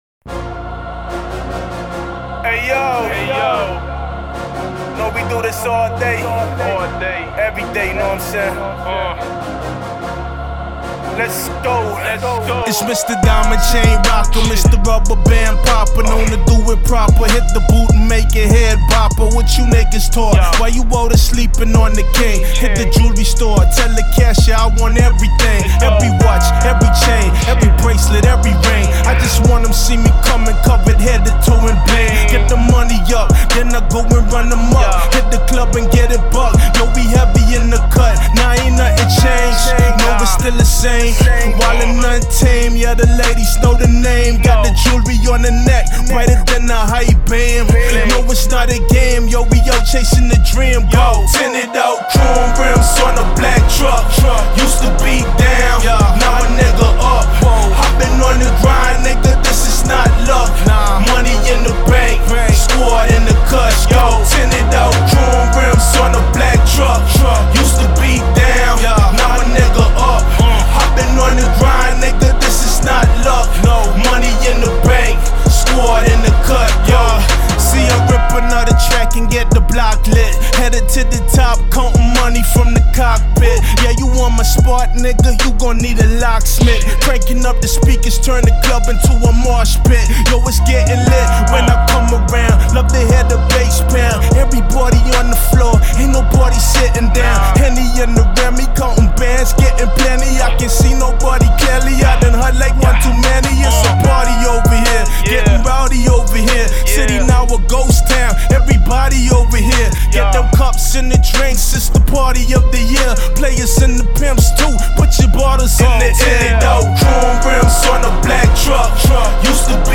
Fully mixed acapella